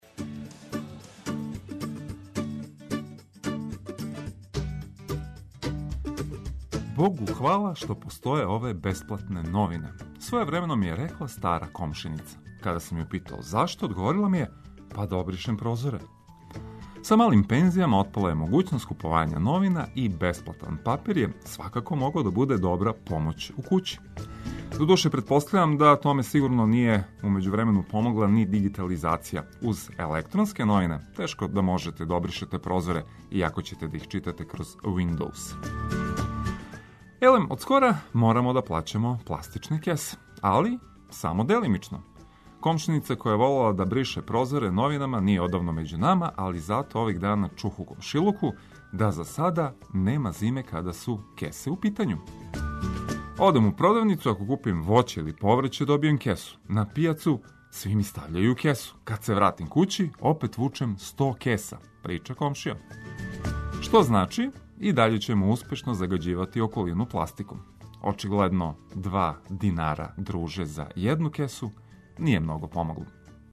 Најважније информације уз музику за пријатно буђење.